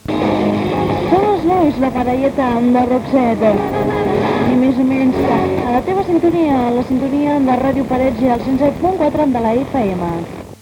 cb58eb21ca9423ce3f640b0efa54c23ada314b46.mp3 Títol Ràdio Parets Emissora Ràdio Parets Titularitat Pública municipal Descripció Identificació de l'emissora.